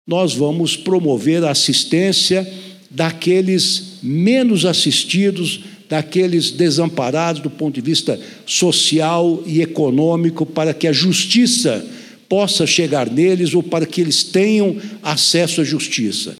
Fala do ministro Ricardo Lewandowski no lançamento do Plano Nacional Defensoria em Todos os Cantos.mp3 — Ministério da Justiça e Segurança Pública
fala-do-ministro-ricardo-lewandowski-no-lancamento-do-plano-nacional-defensoria-em-todos-os-cantos.mp3